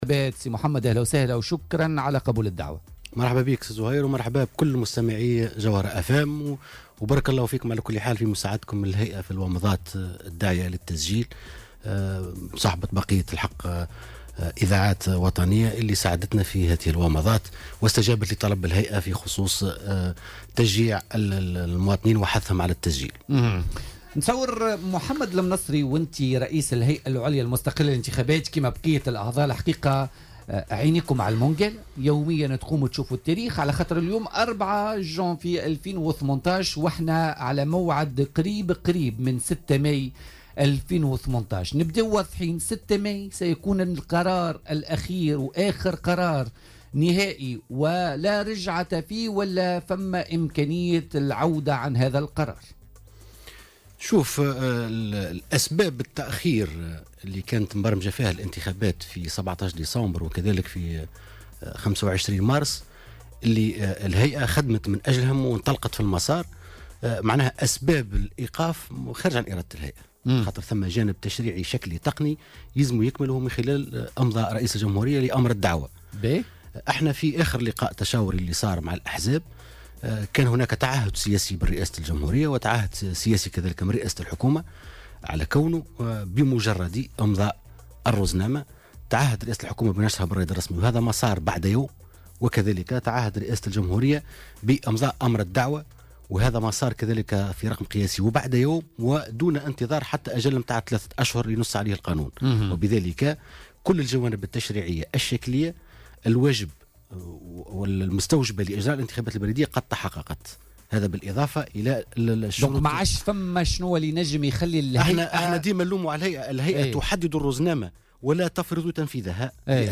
ولاحظ ضيف "بوليتيكا" الهيئة تحدّد الروزنامة ولا تفرض تنفيذها لأسباب خارجة عن ارادتها.